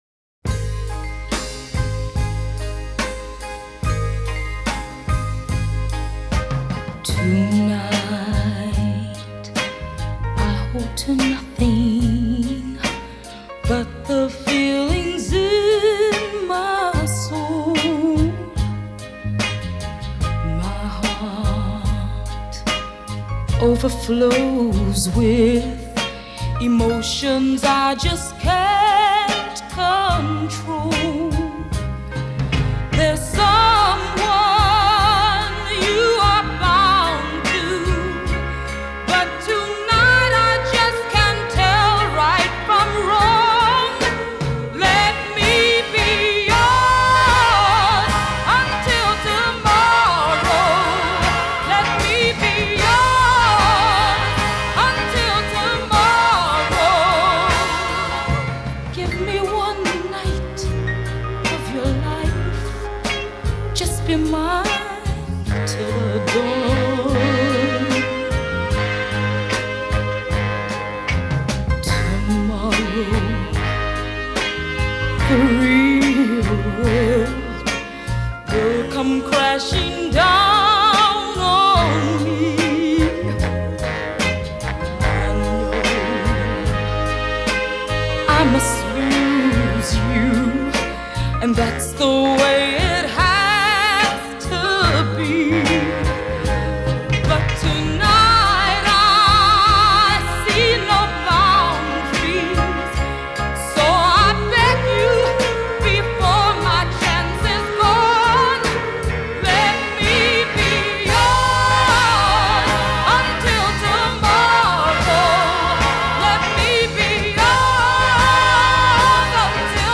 Genre: Funk / Soul